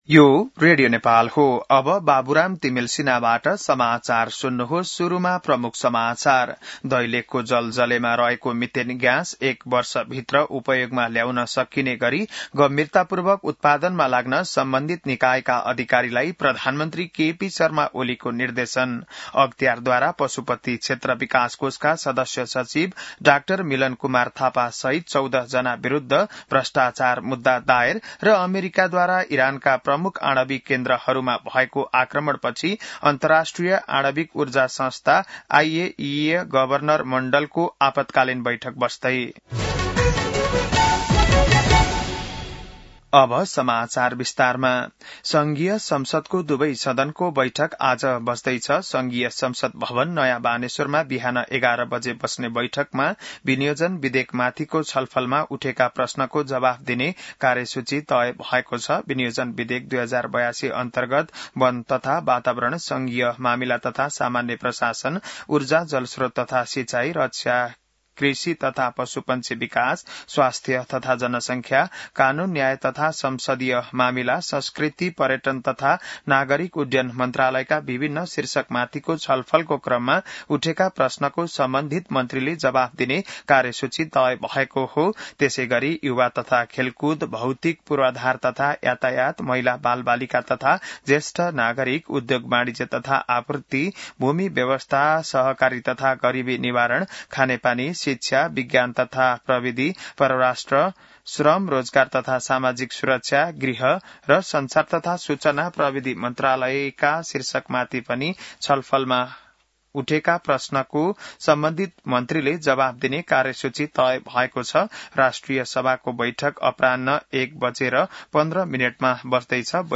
बिहान ९ बजेको नेपाली समाचार : ९ असार , २०८२